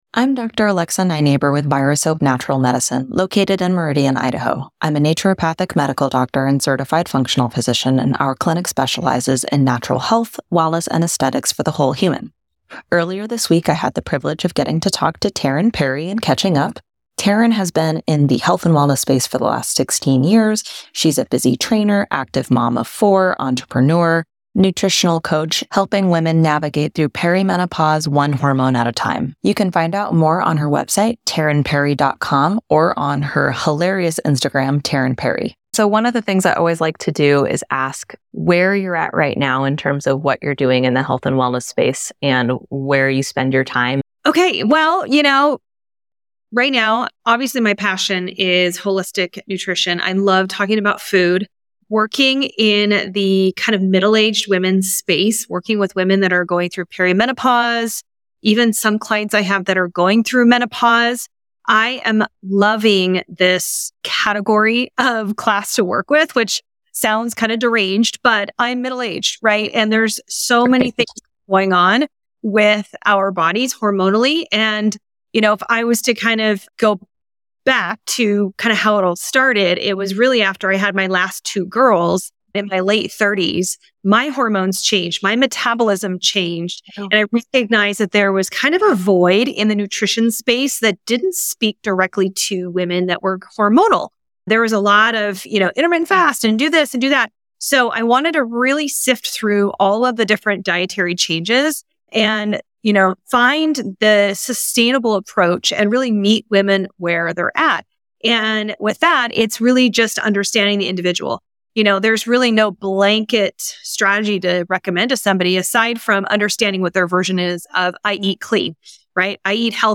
A discussion about the fascinating topic of GLP-1 activation and holistic wellness.